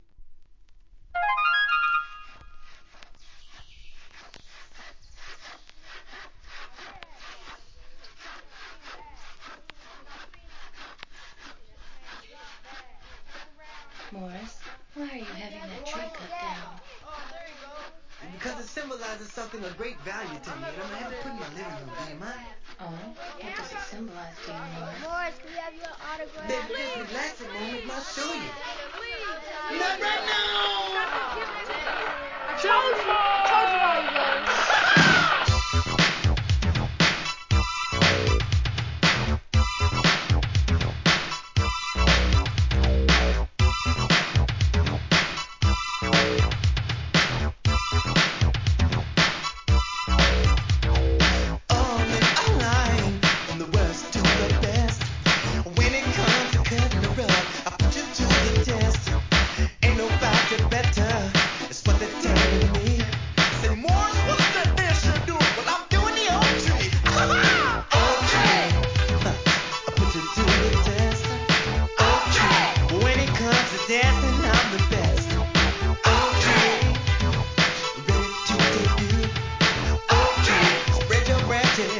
SOUL/FUNK/etc...
ミネアポリス・ファンク!!